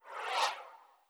SwooshSlide3.wav